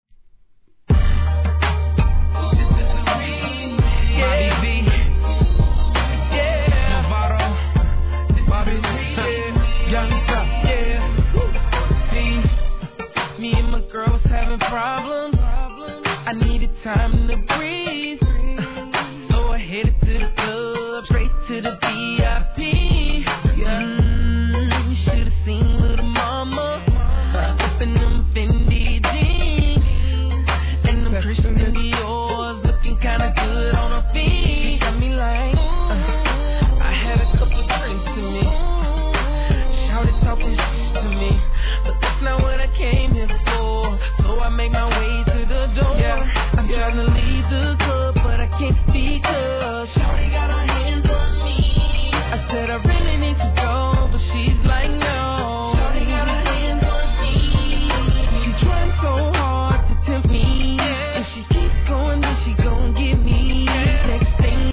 HIP HOP/R&B
極上美メロソングだけを収録したラグジュアリーR&Bコンピの必須アイテムシリーズ第33弾が登場!!